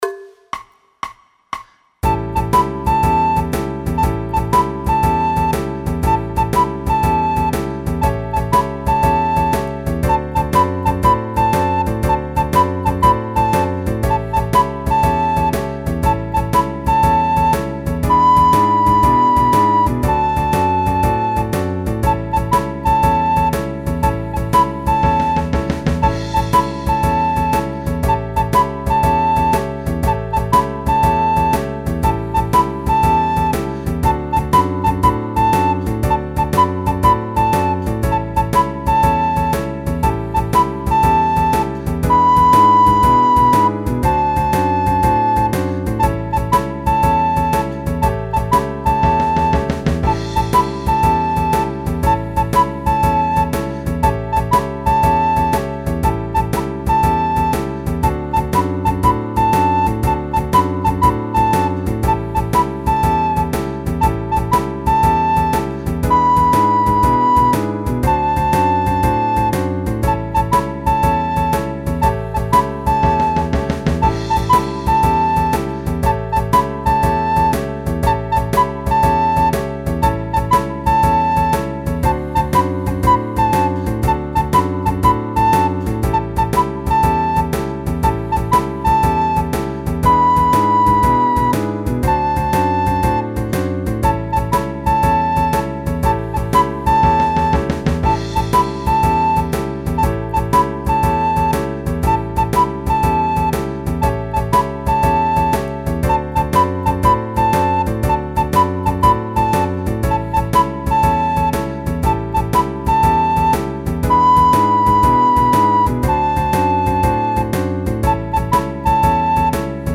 A modo de exemplo escoita esta base musical cunha frauta tocando a melodía.
A Blues, con frauta
a_blues_con_frauta.mp3